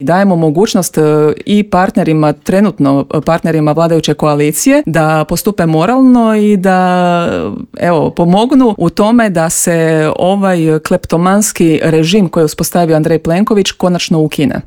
SDP-u je neprihvatljivo da se prvo glasa o opozivu premijera, a tek onda o ustavnim sucima, poručila je jutros u Intervjuu Media servisa zastupnica SDP-a Mirela Ahmetović.